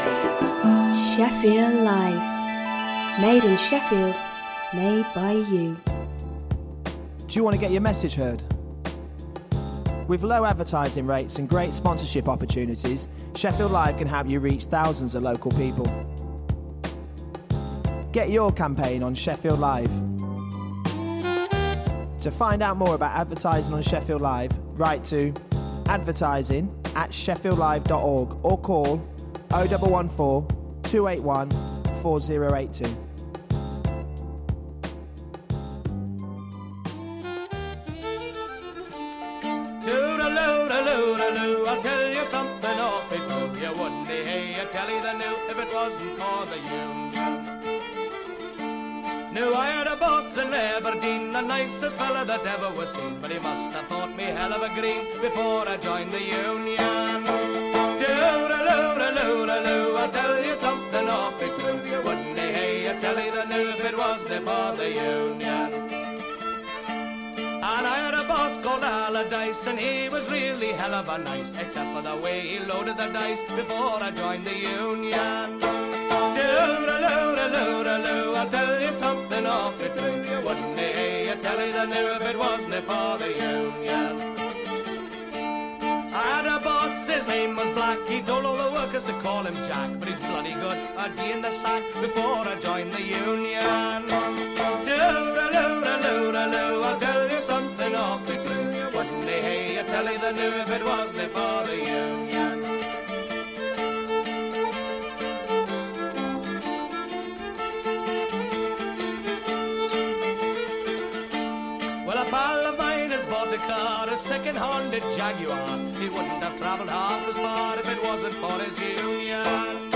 Write Radio is a two hour radio show which showcases new and local writing from the people of South Yorkshire.